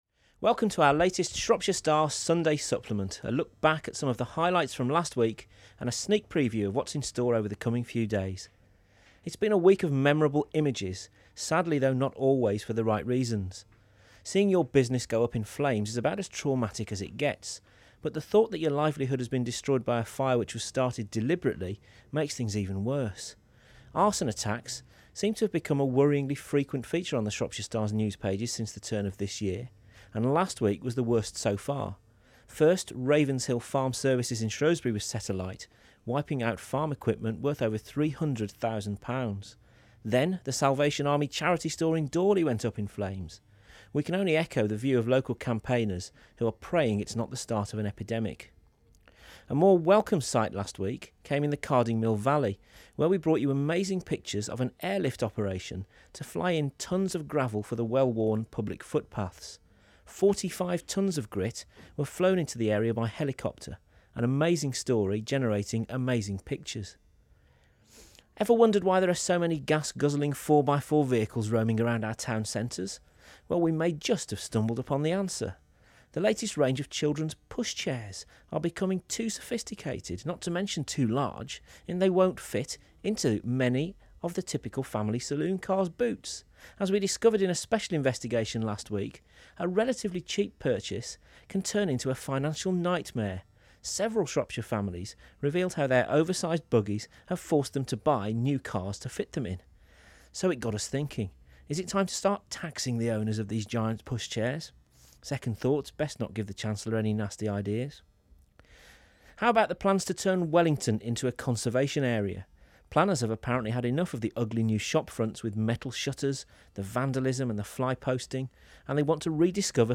Lively audio from the Shropshire Star team.
The team behind the Shropshire Star, one of the UK's top selling regional newspapers, bring you regular broadcasts from the region.